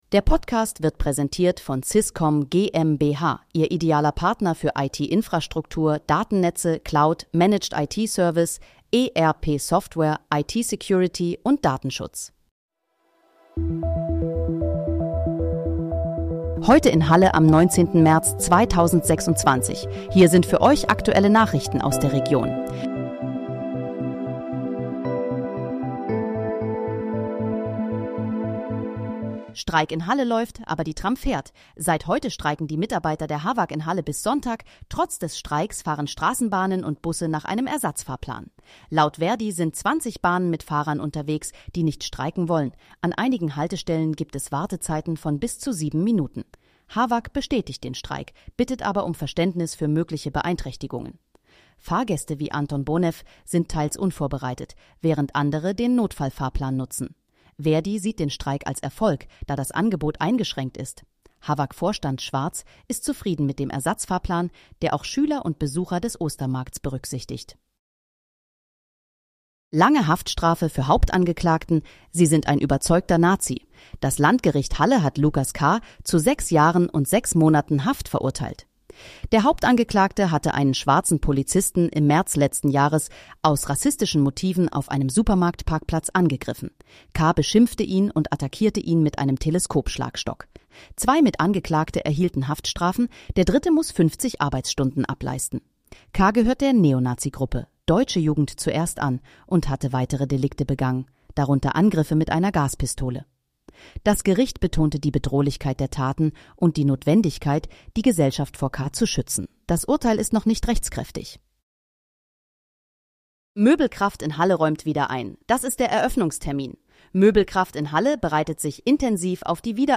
Heute in, Halle: Aktuelle Nachrichten vom 19.03.2026, erstellt mit KI-Unterstützung
Nachrichten